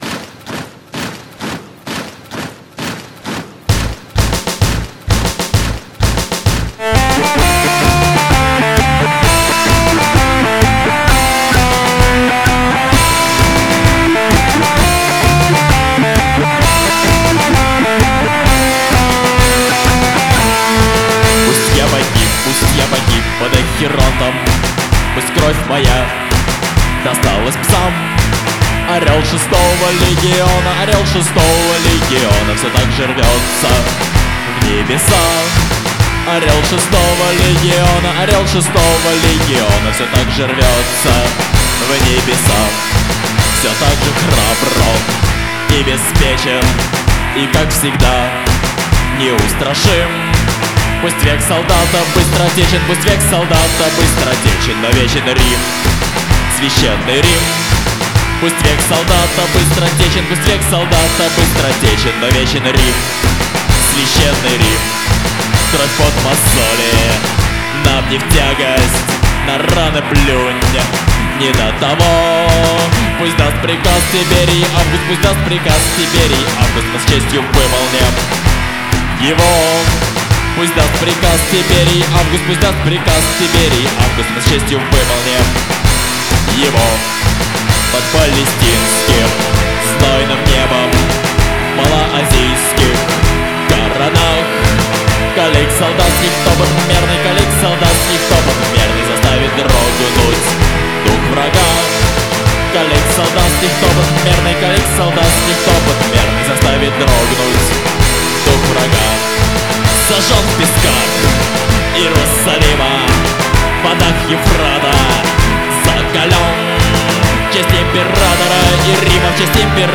А эта - давно стала гимном археологов. Немного агрессивное исполнение.